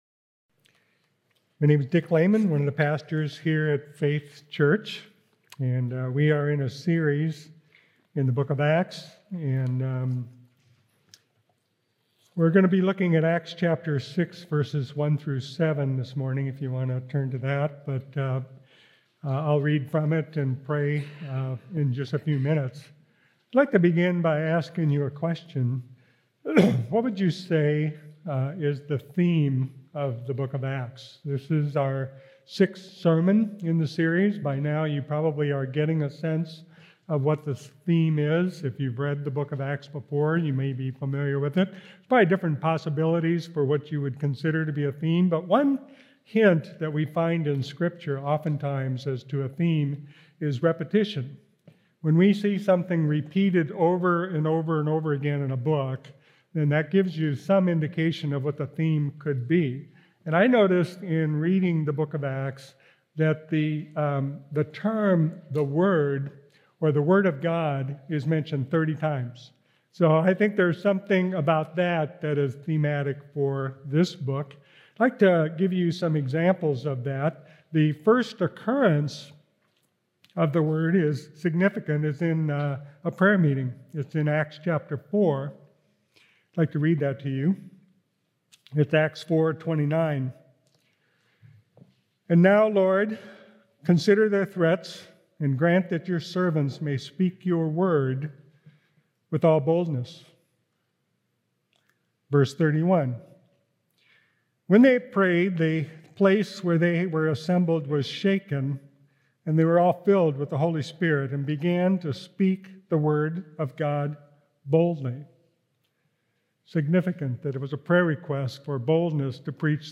Nov 29th Sermon